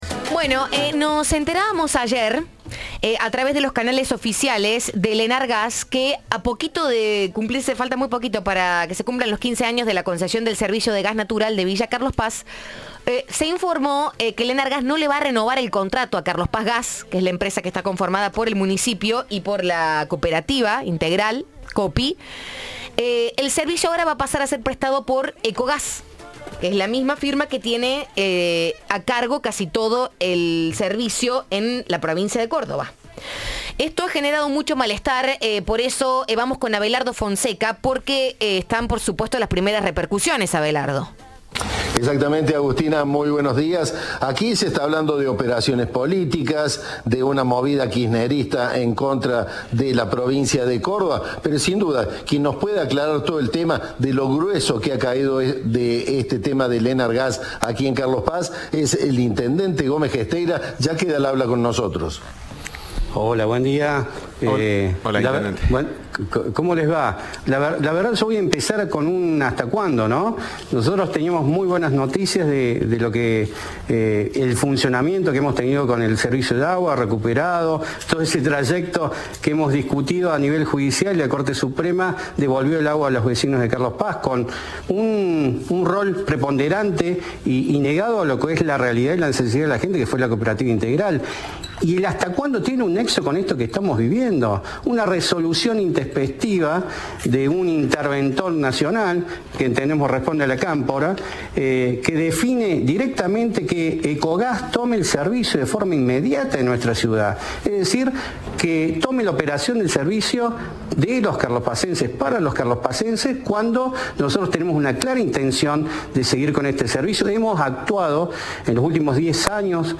"Hay una decisión fuerte de castigar al carlospacense", dijo a Cadena 3 Daniel Gómez Gesteira, intendente de Carlos Paz.
Entrevista de Siempre Juntos.